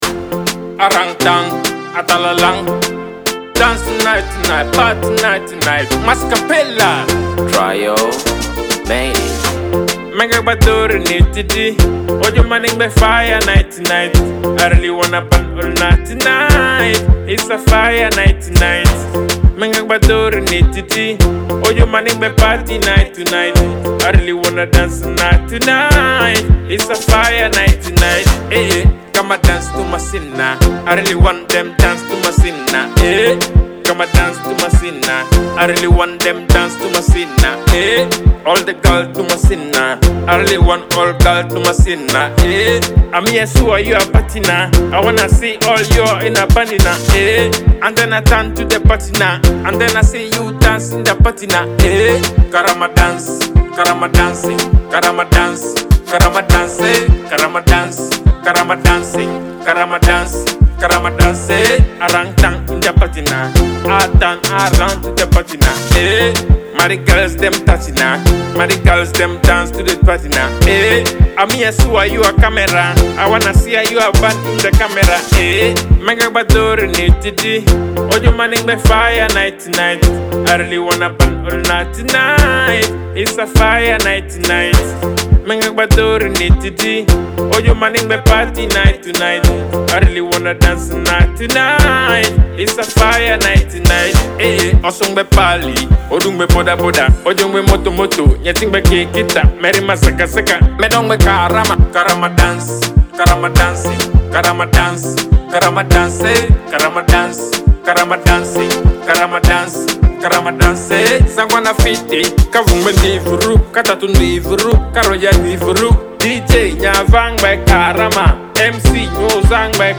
Genre: Dancehall